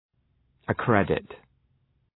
Προφορά
{ə’kredıt}